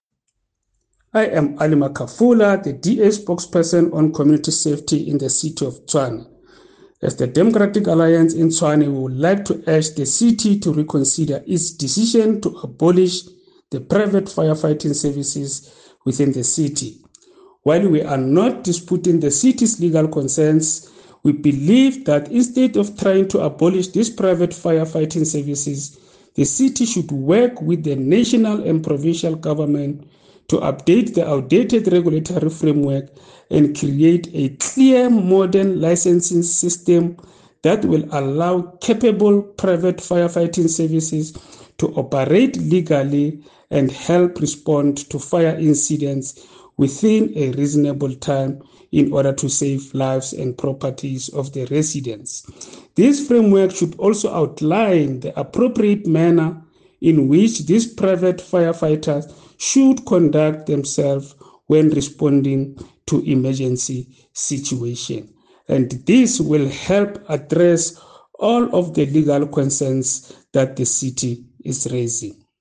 Note to Editors: Please find English and Setswana soundbites by Cllr Alfred Makhafula